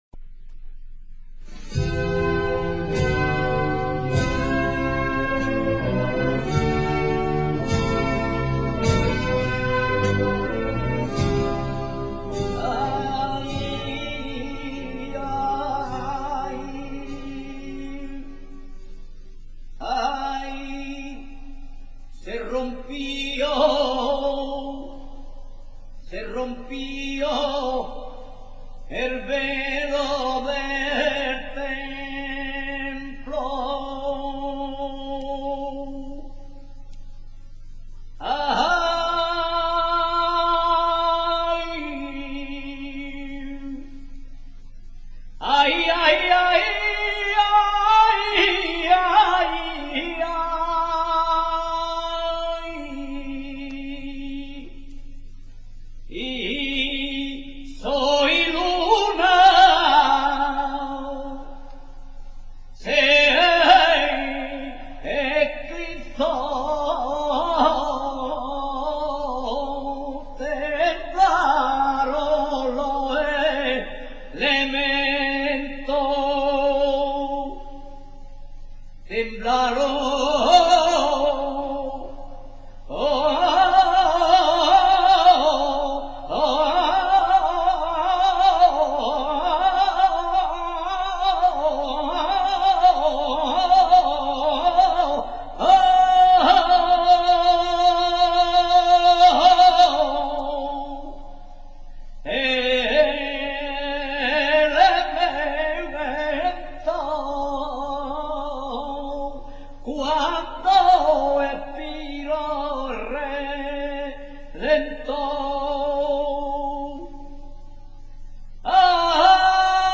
saeta.mp3